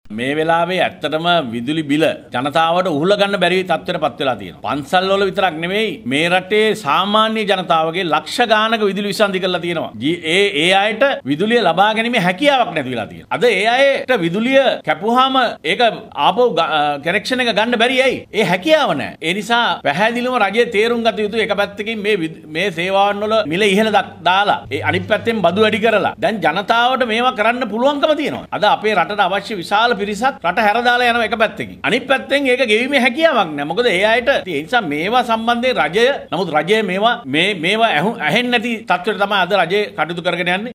එහි පාර්ලිමේන්තු මන්ත්‍රී ජේ.සී. අලවතුවල මහතා සදහන් කලේ මේ හේතුවෙන් ජනතාව දැඩි පිඩාවට පත්ව ඇති බවයි. කොළොඹ පැවති මාධ්‍ය හමුවකදී ඔහු මේ බව පැවසුවා .